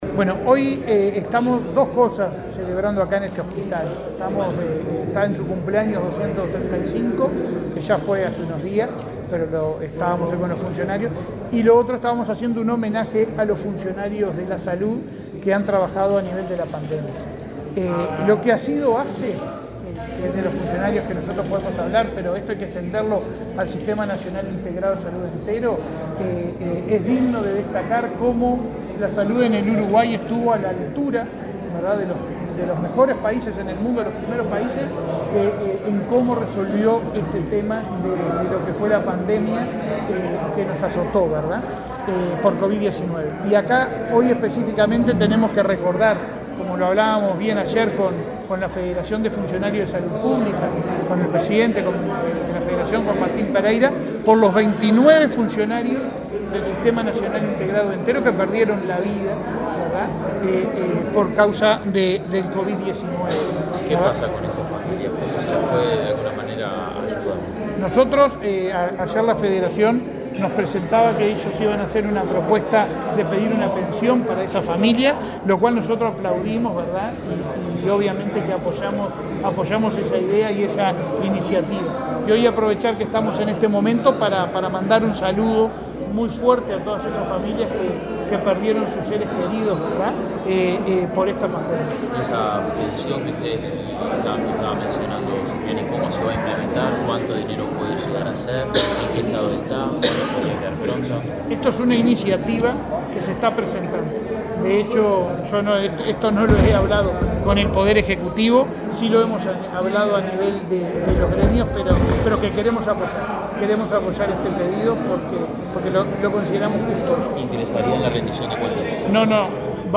Declaraciones del presidente de ASSE, Leonardo Cipriani
El presidente de ASSE, Leonardo Cipriani, participó en la celebración por el 235.° aniversario del hospital Maciel. Luego dialogó con la prensa.